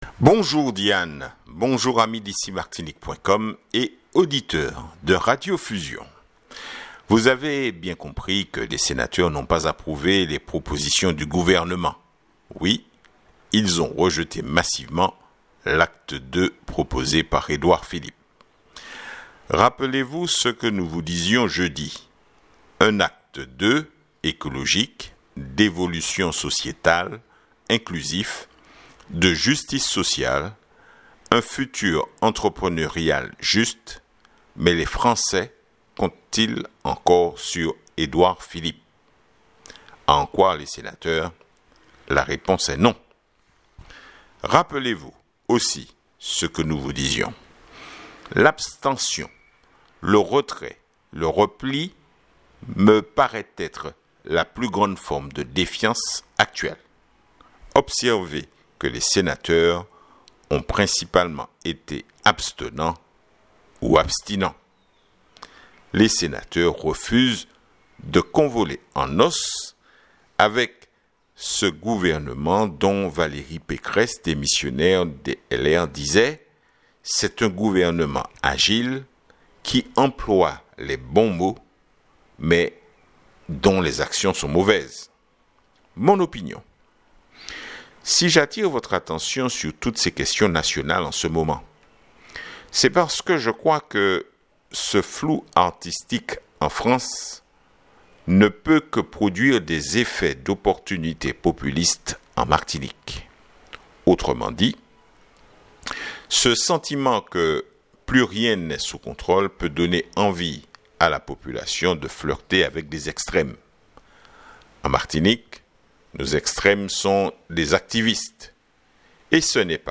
Editorial du Jour / les sénateurs disent non à Edouard Philippe !